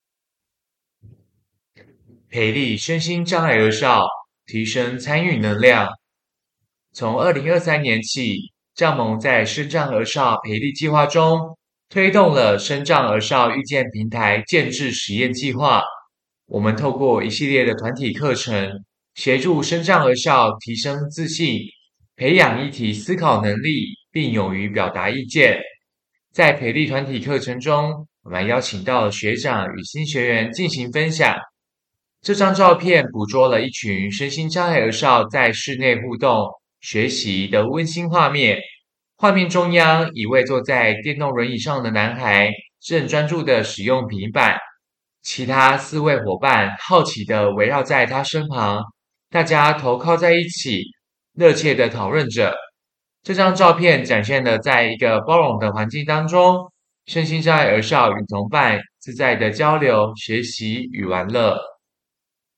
所有文字內容會轉成語音檔，每幅作品旁都附有語音 QR-Code，讓視障朋友或不便閱讀的民眾能掃描聆聽，用「聽」的方式來欣賞作品。